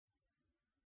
Аудиокнига Шесть цифр | Библиотека аудиокниг